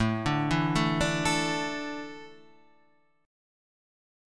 Asus4 chord {5 5 2 2 3 5} chord